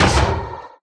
Sound / Minecraft / mob / irongolem / hit1.ogg
hit1.ogg